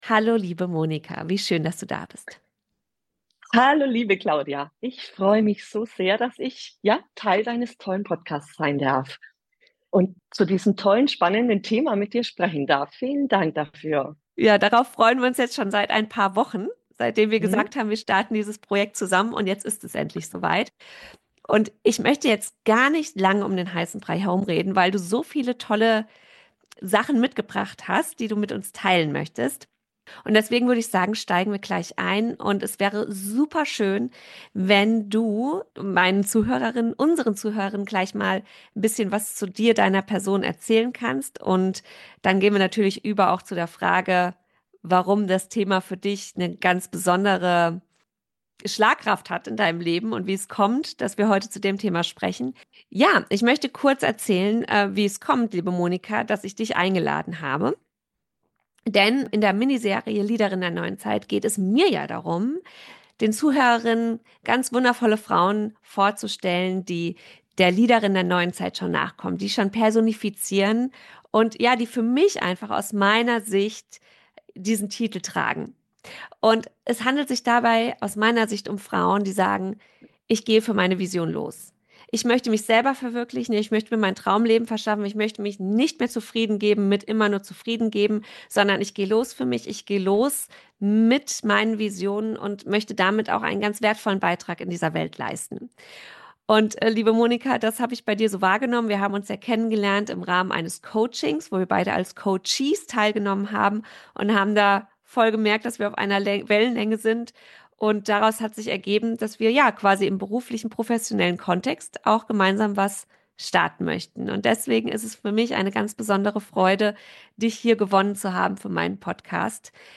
#54 Wie du deine weibliche Intuition als kraftvollen Lebenskompass nutzt: Interview